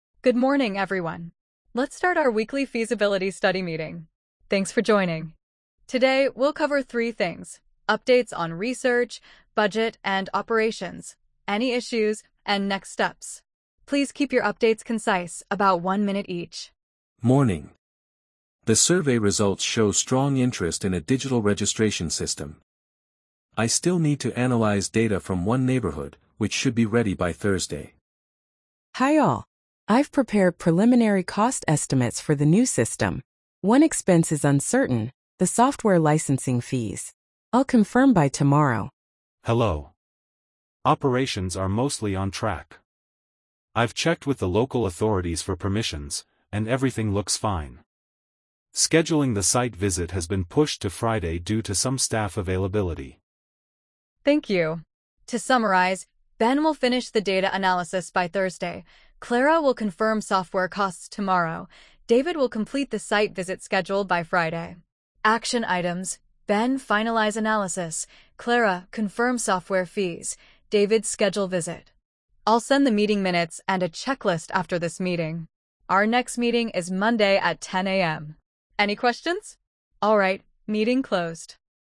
🤝 A team gathers for a quick weekly progress meeting.